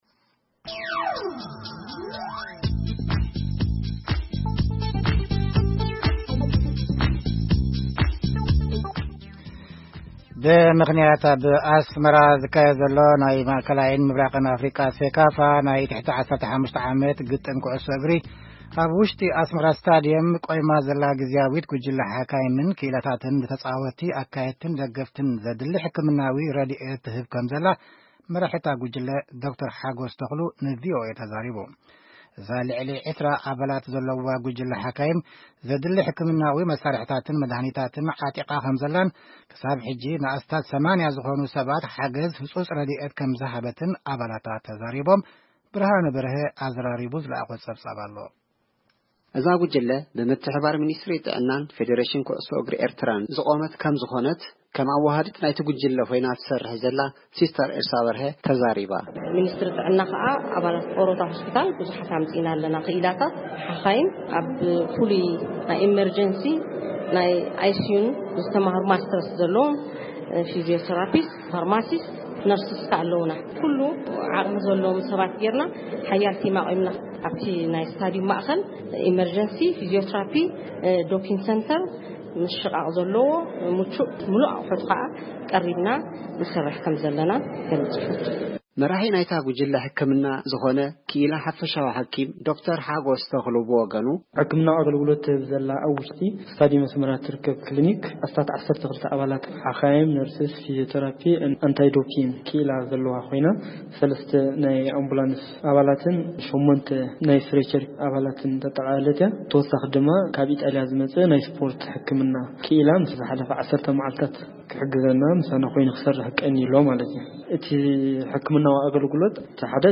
እዛ ልዕሊ 20 ኣባላት ዘለዋ ጉጅለ ሓካይም፡ ዘድልይ ሕክምናዊ መሳርሒታትን መድሃኒታትን ዓጢቃ ከምዘላን ክሳብ ሕጂ ንኣስታት 80 ዝኮኑ ሰባት ሓገዝ ህጹጽ ረድኤት ከምዝሃበትን ኣባላታ ተዛሪቦም።